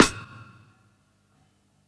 sf_bli_snr.wav